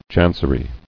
[chan·cer·y]